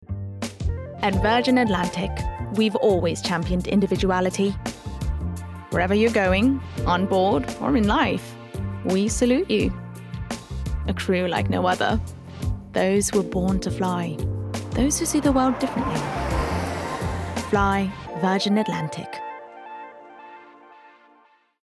Versatile/Contemporary/Youthful
Virgin Atlantic (British accent)